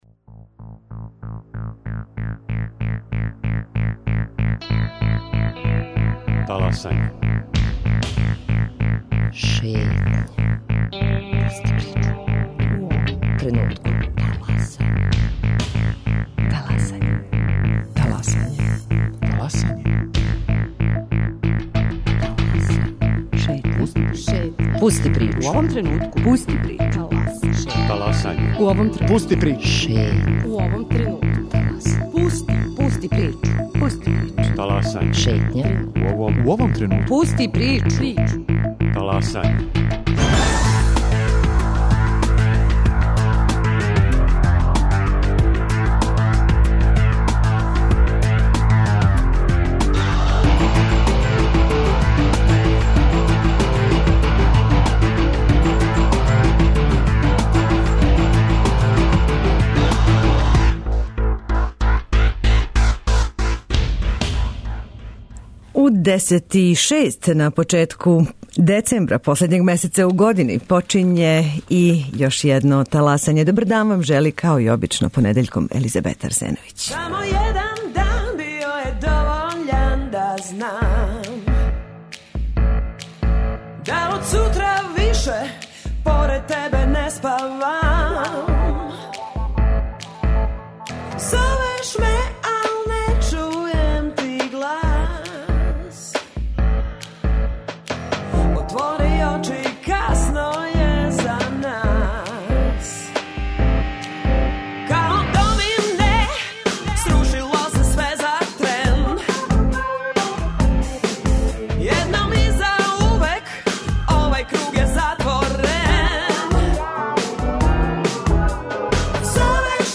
Упознаћете Друштво Словенаца "Сава" у Београду, чији хор, за наше слушаоце, пева "Ој, Мораво"